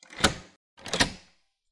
双层门锁
描述：门锁，固定螺栓，闩锁或翻转开关的声音。门被锁在外面。
Tag: 打开 闩锁 关闭 解锁 拟音 死栓 解锁ING 关闭 锁止 转鼓 螺栓 解锁 锁定 锁定 安全 关闭 锁止